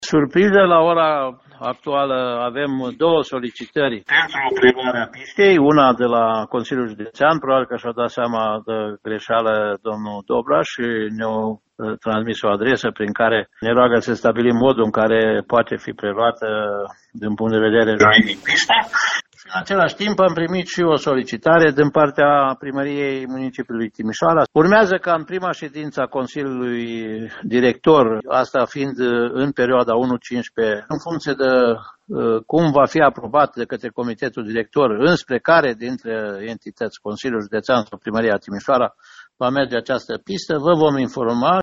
Directorul Administrației Bazinale de Apă Banat, Titu Bojin, spune că o decizie privind administrarea pistei va fi luată în următoarele două săptămâni.